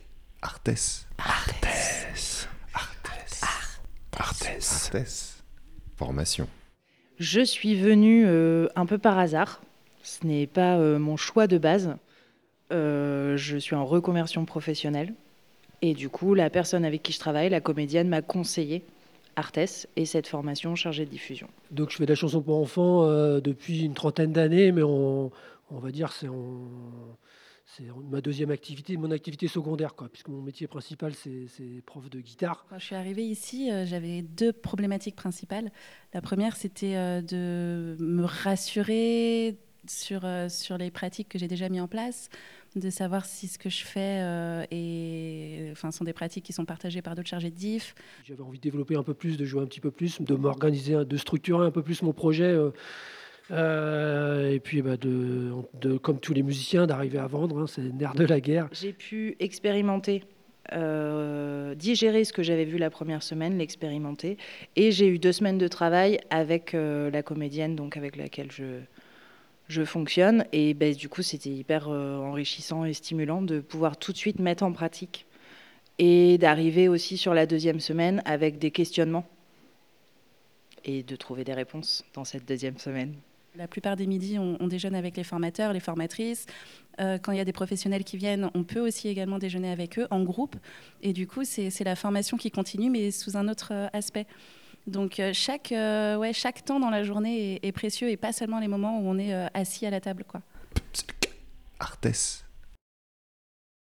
Pour fêter les 15 ans du cycle "Réussir sa mission de chargé.e. de diffusion", en fin d'année dernière, nous avons pris le temps d'enregistrer plusieurs participants avec un bon micro (merci aux intervenants de la formation podcast...).